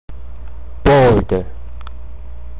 這才是道地的美式發音喔！
輕音節的a要讀成 / a /。